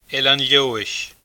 Pronunciation
Pronunciation: [elan ˈʎɔːəʃ]